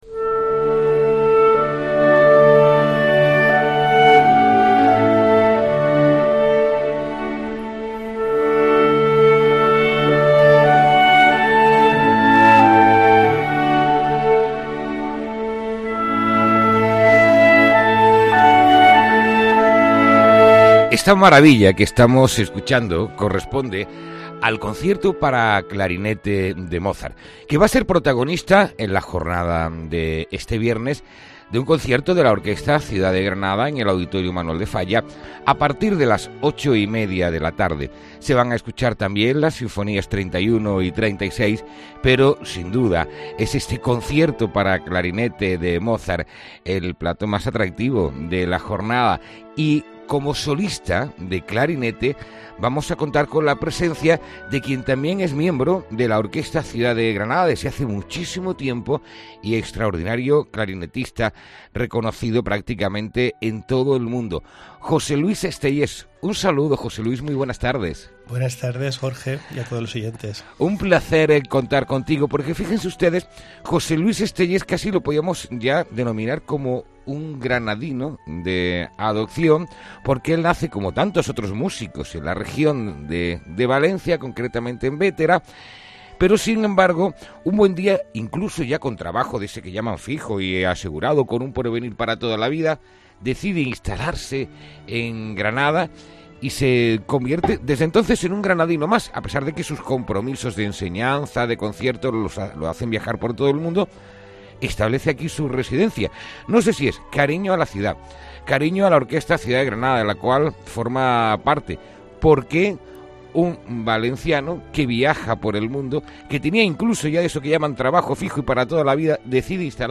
Concierto para clarinete de Mozart